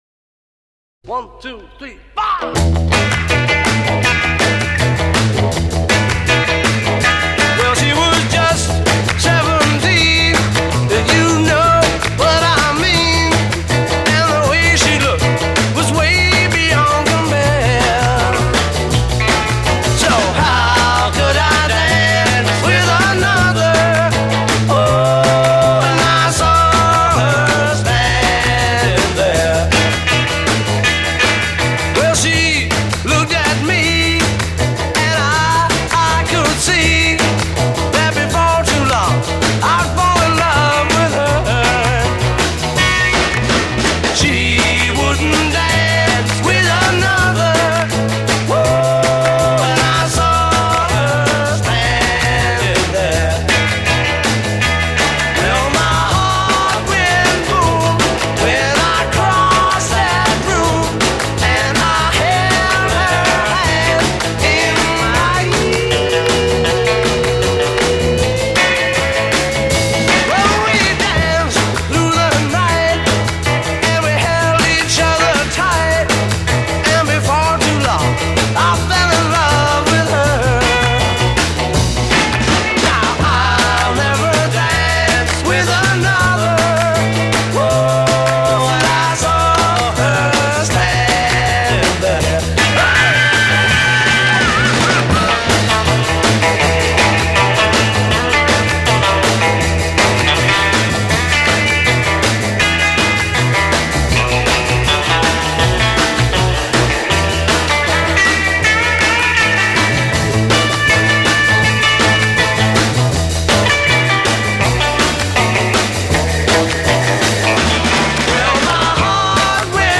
voice and rhythm guitar
voice and bass guitar
lead guitar
drums
A2 refrain 0:20 8 Vocal duet. b1
A1 verse 1:35 8 Guitar solo.
A" coda 2:37 8 Repeat hook twice (thrice including refrain).